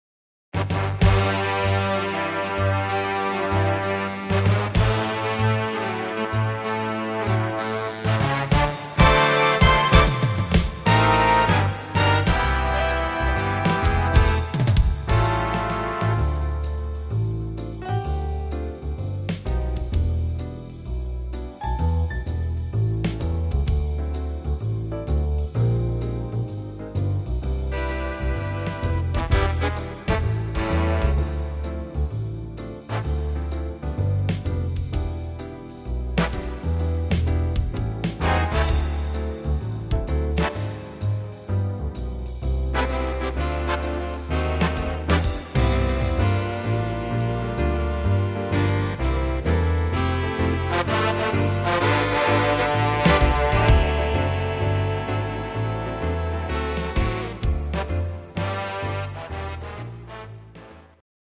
instr. Big Band